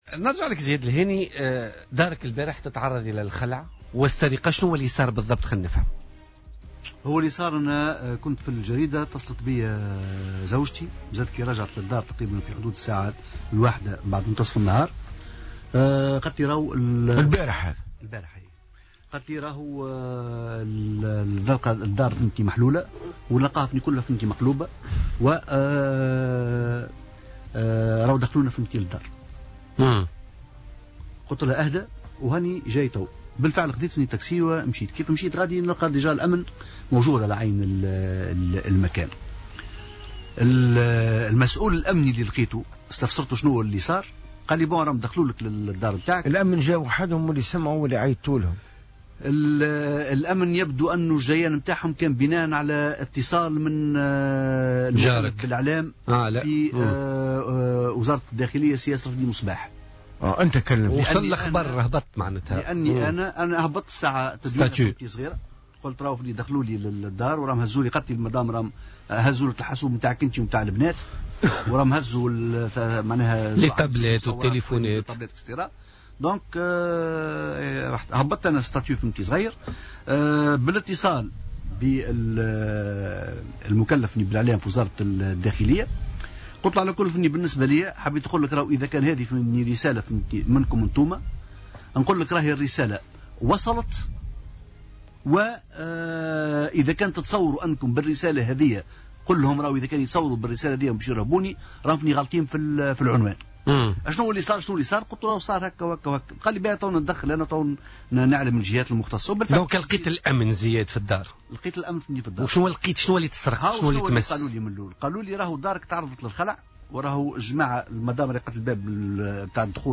Le journaliste Zied El Héni était l'invité
dans l'émission Politica de ce mercredi 15 février 2017.